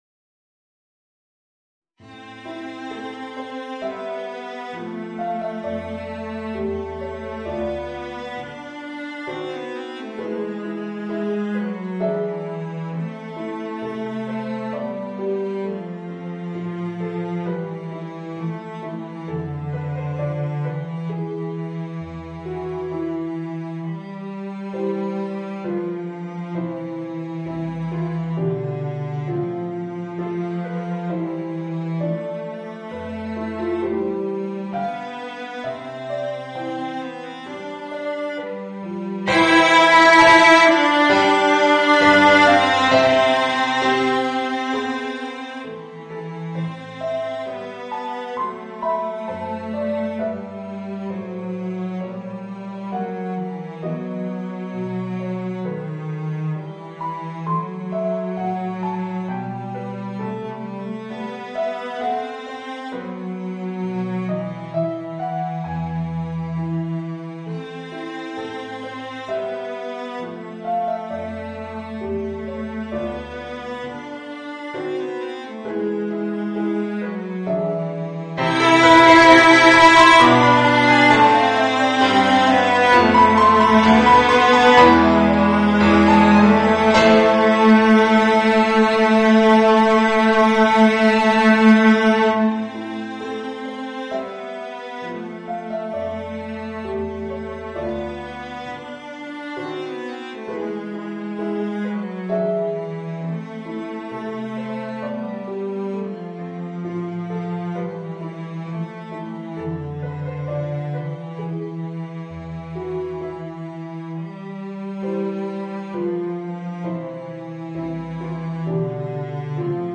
Voicing: Violoncello and Organ